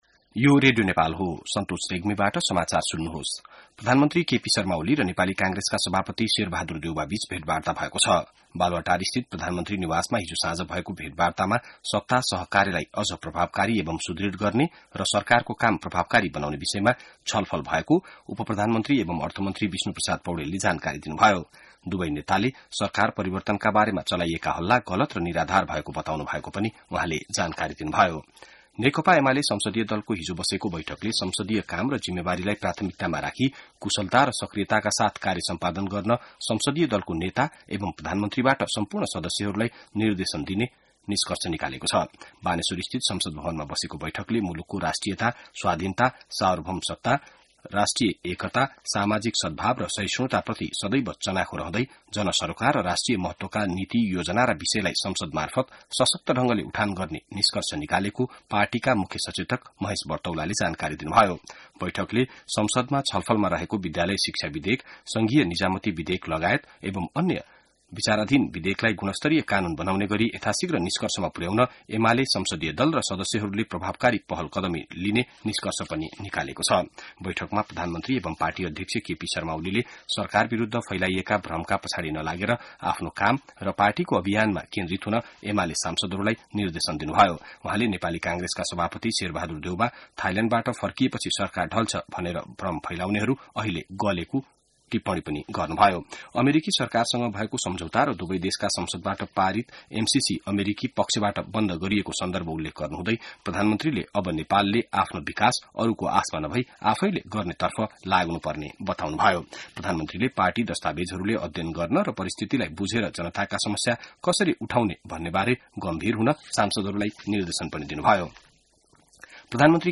बिहान ६ बजेको नेपाली समाचार : १४ वैशाख , २०८२